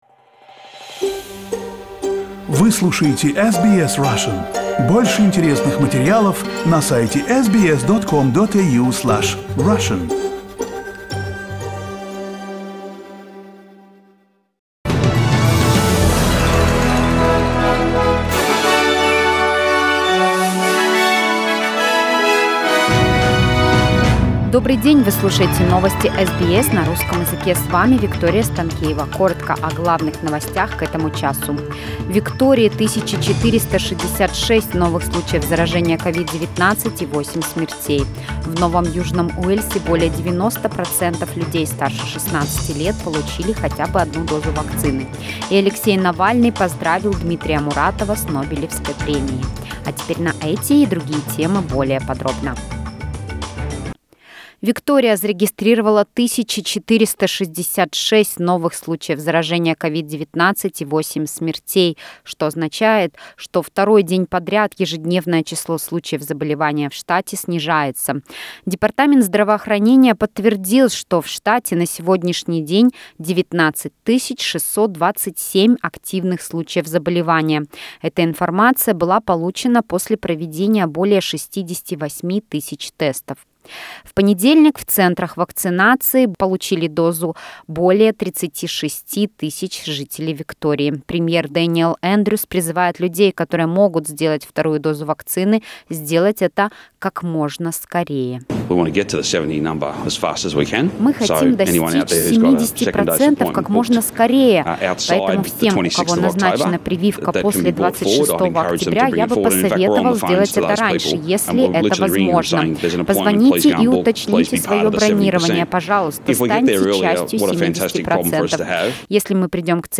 SBS news in Russian - 12.10